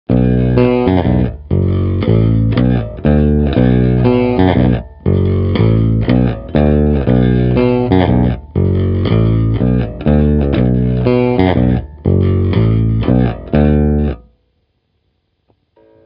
kobylka